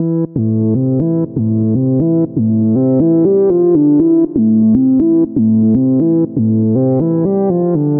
摇滚 男声 另类
bmin key_of_b 吉他 贝斯 合成器 alt_pop